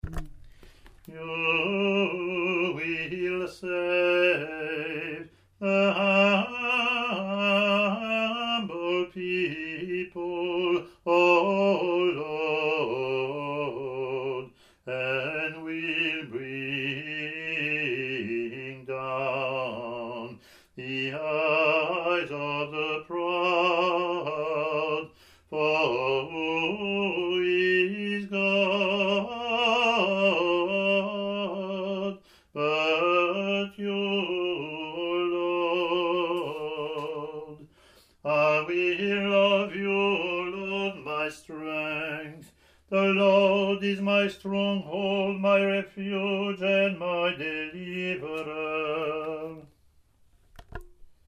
English antiphon – English verse